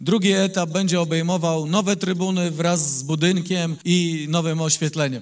– Ale to jeszcze nie koniec prac. Czeka nas wkrótce drugi etap modernizacji – zapewnia Wojciech Iwaszkiewicz, burmistrz Giżycka.